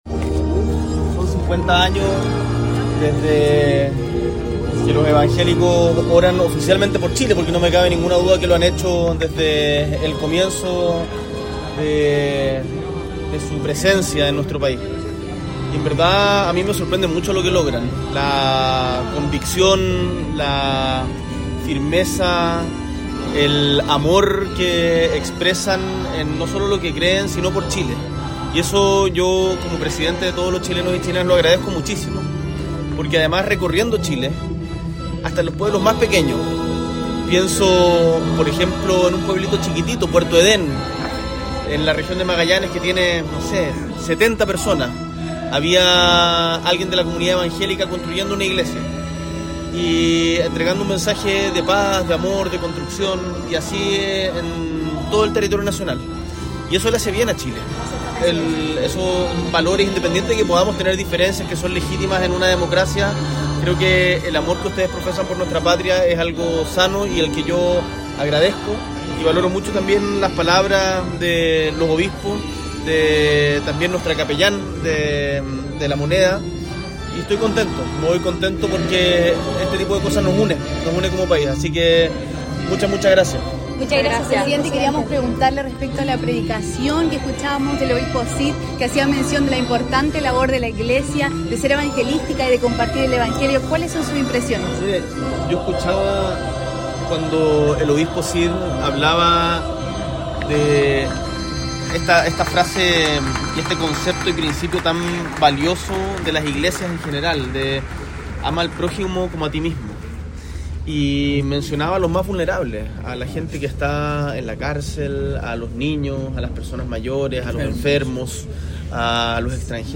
El domingo 14 de septiembre, el Presidente de la República, Gabriel Boric Font, junto a ministras, ministros, subsecretarias y subsecretarios de Gobierno, participó del Servicio de Acción de Gracias de las Iglesias Evangélicas de Chile 2025, realizado en la Iglesia Metodista Pentecostal de Puente Alto.